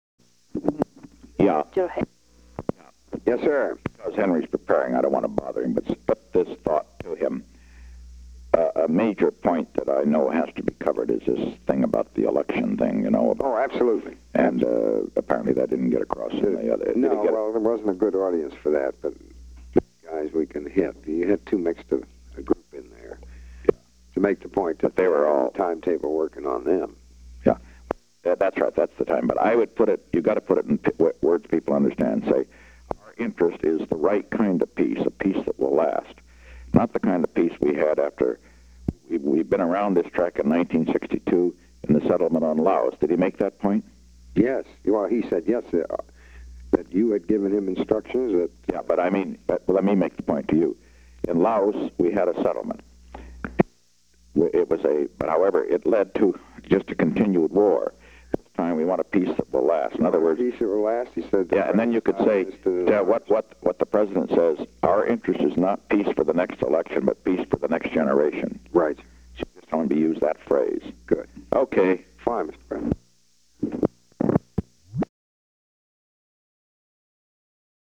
Conversation No. 32-57
Location: White House Telephone
The President talked with Alexander M. Haig, Jr.